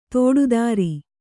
♪ tōḍu dāri